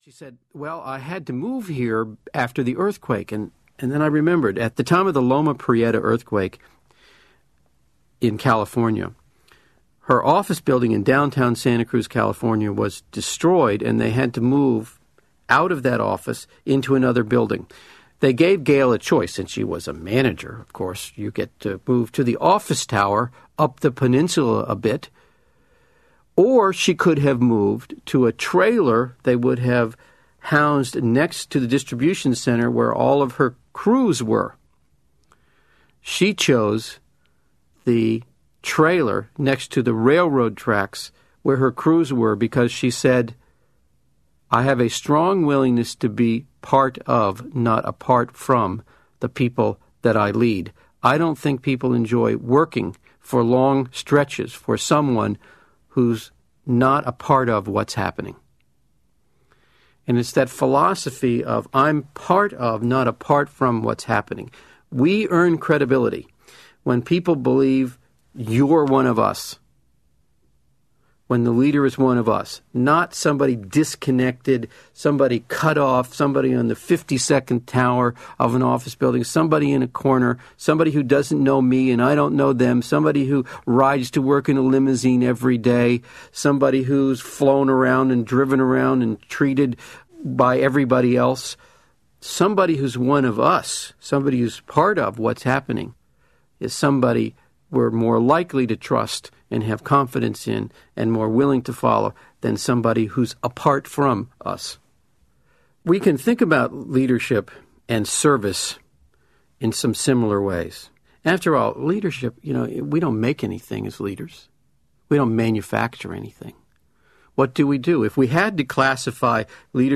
Achieving Credibility Audiobook
Includes a special introduction by Tom Peters.